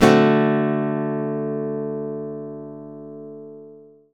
OVATION D7.wav